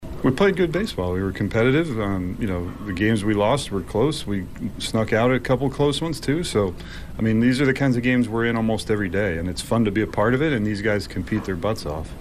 KC Royals manager Matt Quatraro says that he liked his team’s effort on the road trip